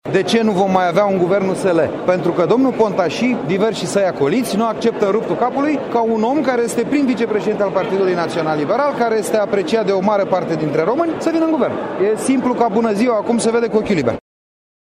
Ascultați declarația lui Crin Antonescu: